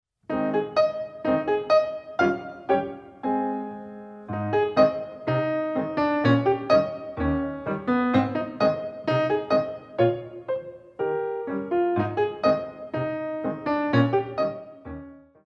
Releves A La Barre